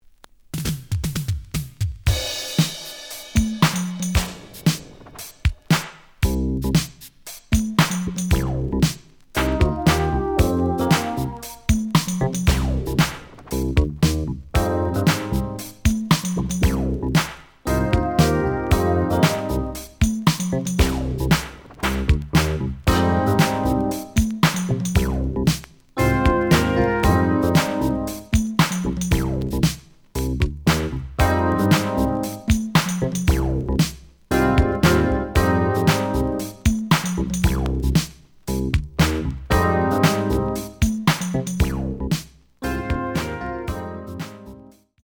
The audio sample is recorded from the actual item.
●Genre: Soul, 80's / 90's Soul
Slight damage on both side labels. Plays good.)